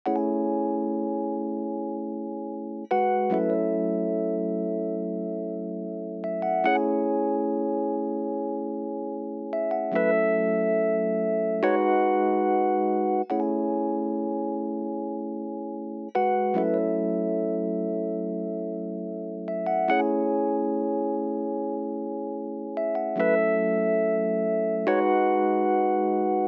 02 rhodes A1.wav